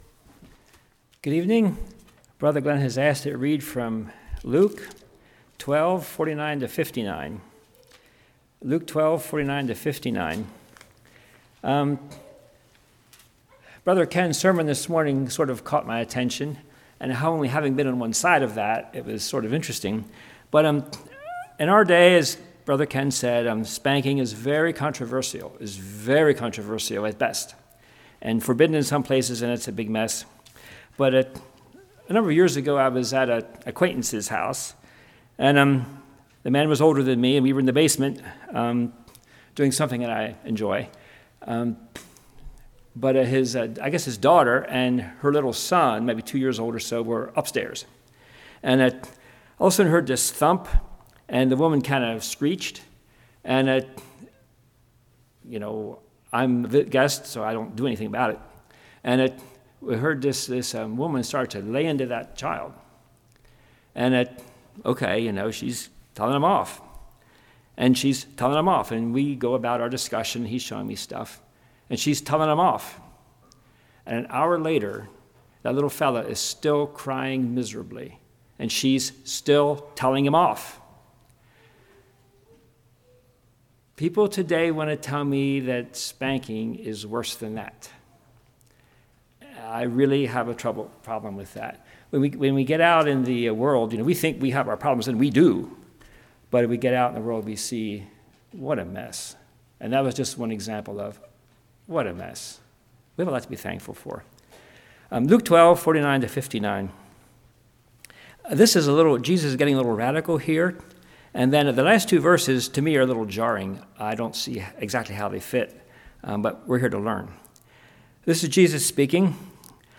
Luke 12:49-59 Service Type: Evening Refining our Faith Through Fire.